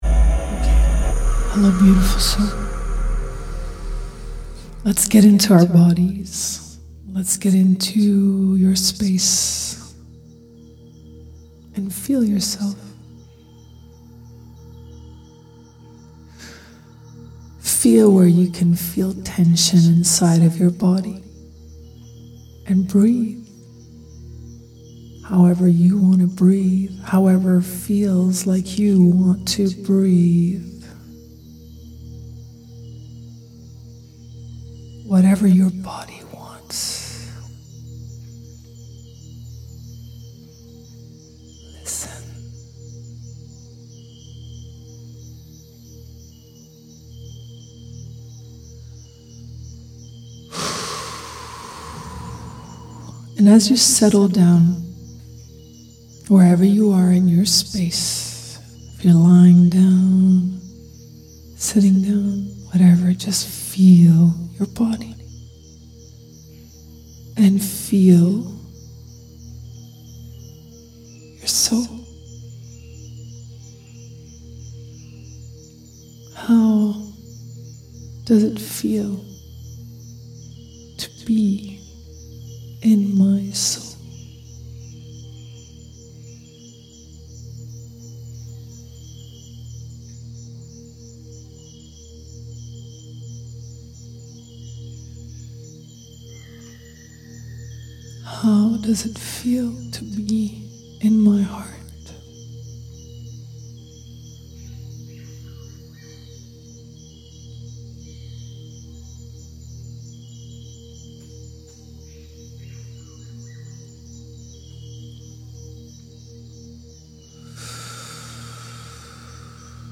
Welcome to this beautiful meditation, designed to gently guide you into deep relaxation and elevate your energy to a blissful frequency. Through this healing journey, we will immerse every cell of your body in powerful golden light, helping you to feel deeply relaxed, restored, and revitalized.
Feel-the-heal-KK-sound-journey-2.mp3